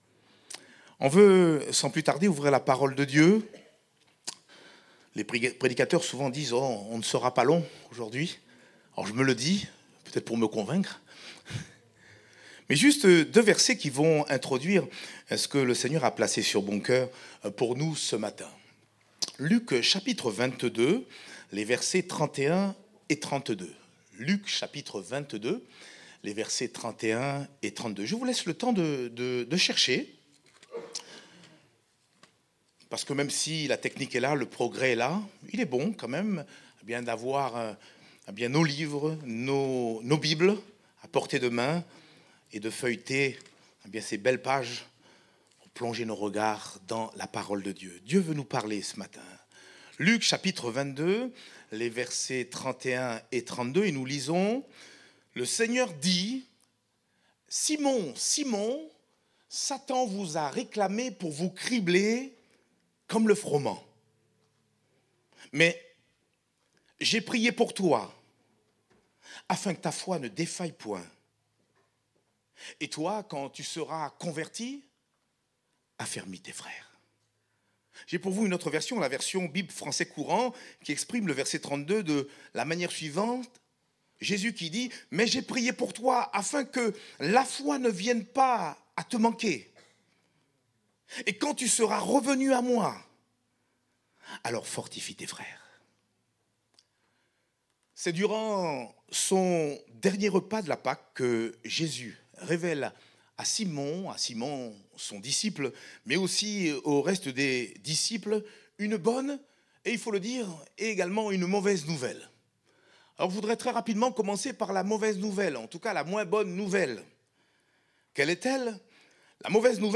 Date : 17 novembre 2024 (Culte Dominical)